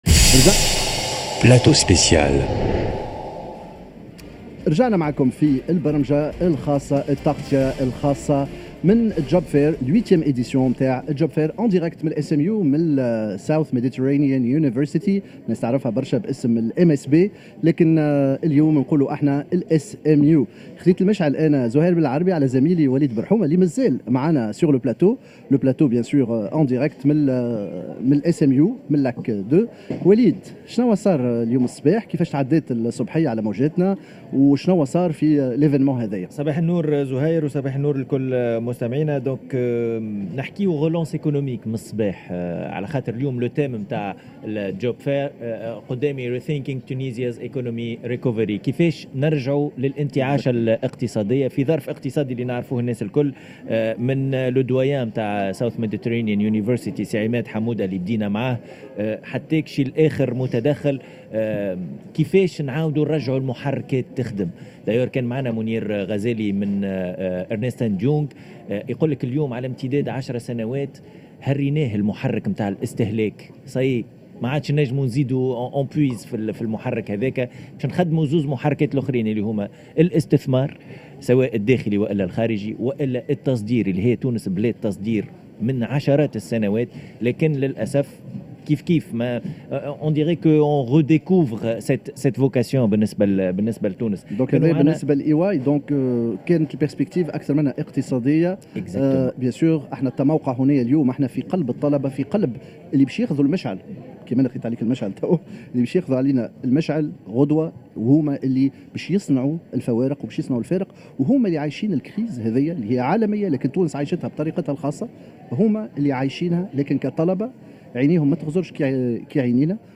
La relance économique poste COVID-19 Salon de L'Emploi SMU